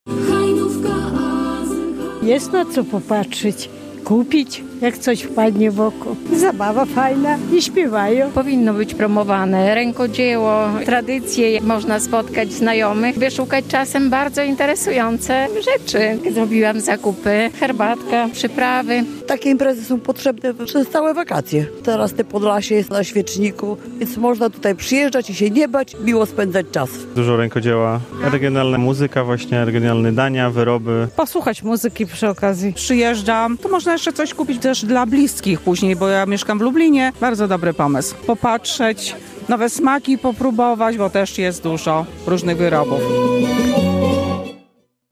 W Parku Miejskim w Hajnówce zorganizowano Jarmark Żubra.
Przyjechałam z Lublina, spróbować nowych smaków, bo też jest dużo nowych wyrobów - mówili uczestnicy jarmarku.